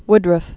(wdrəf, -rŭf)